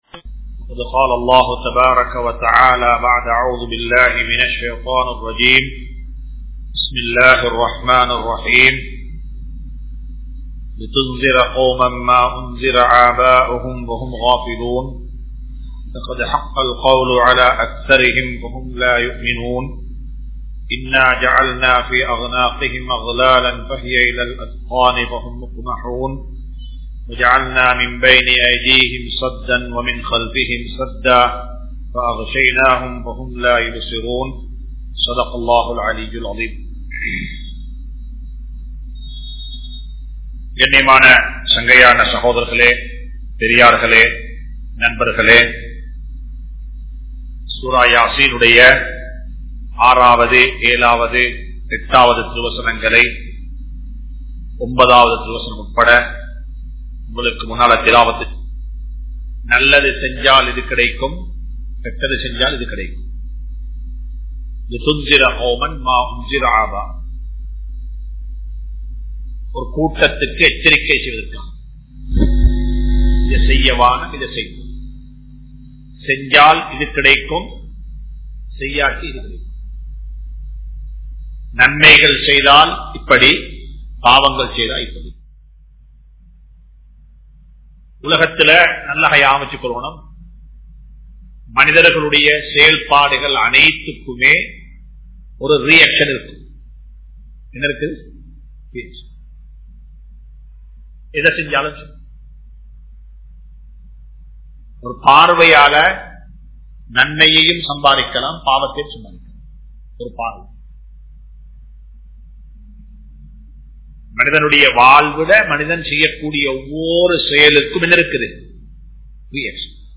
Are You Ready For Death? (Thafseer Lesson 178) | Audio Bayans | All Ceylon Muslim Youth Community | Addalaichenai
Majma Ul Khairah Jumua Masjith (Nimal Road)